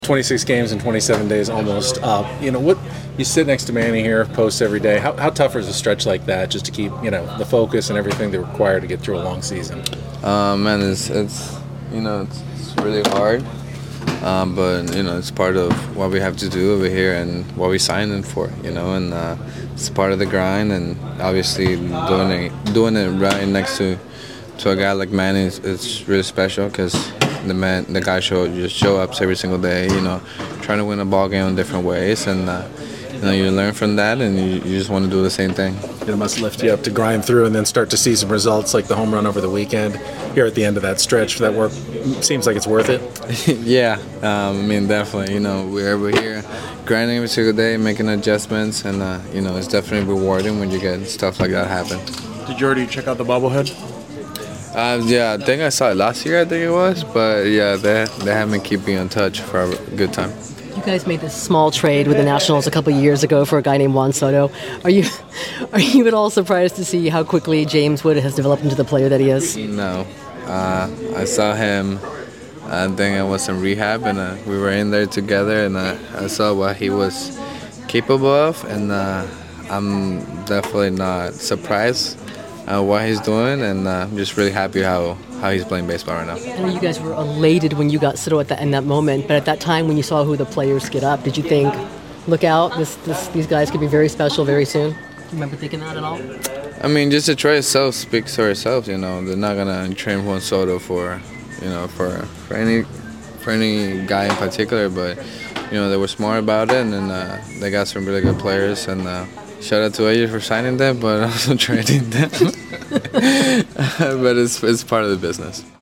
San Diego Padres outfielder Fernando Tatis Jr. speaks with the media before the team's series opener against the Washington Nationals.